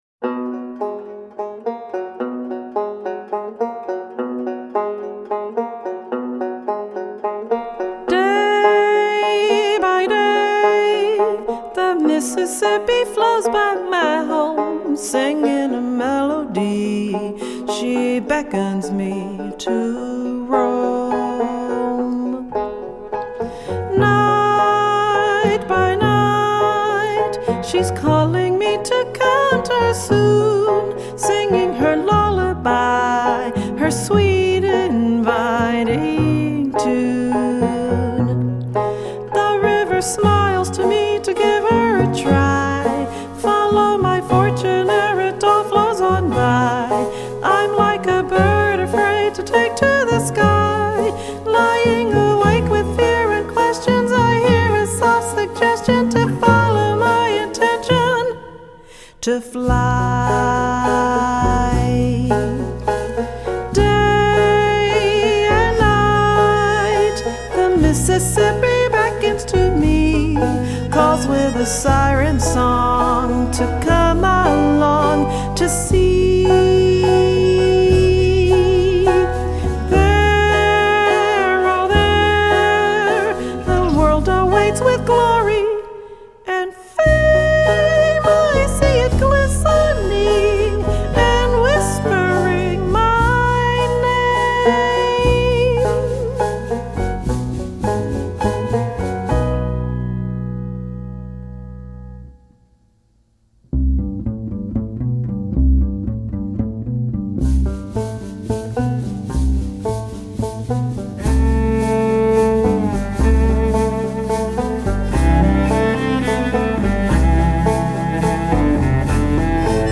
solo song with jazz stylings